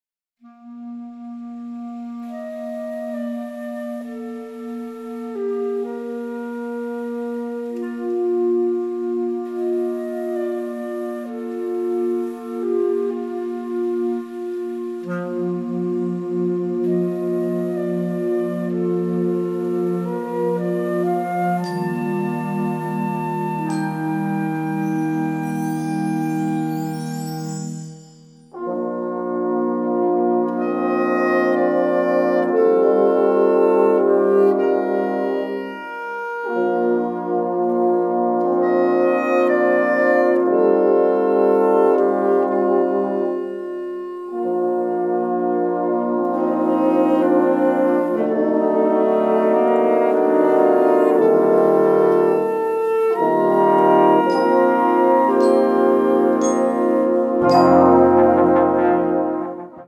Gattung: Konzertmusik
Besetzung: Blasorchester